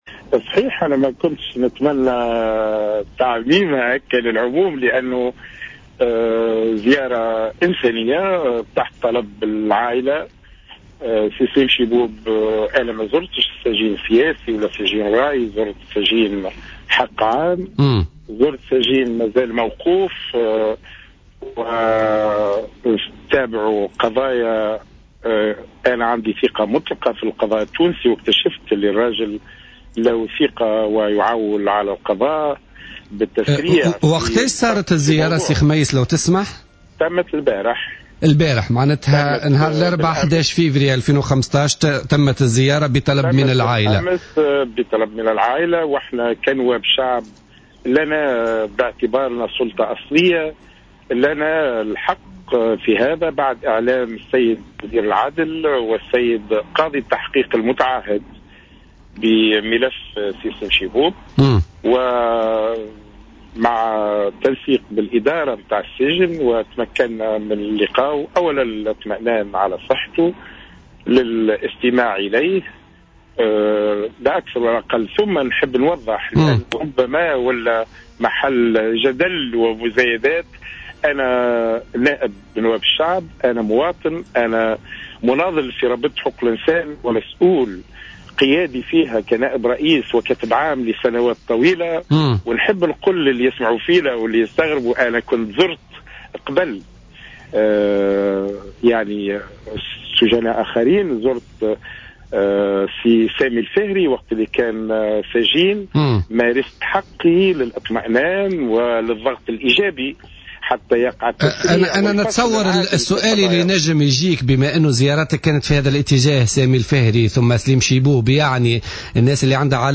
أكد القيادي في نداء تونس، خميس قسيلة في تصريح للجوهرة أف أم اليوم الخميس، أنه أدى أمس الاربعاء 11 فيفري زيارة لصهر الرئيس الأسبق، سليم شيبوب في سجن المرناقية رفقة النائب عن الحركة عبد العزيز القطي.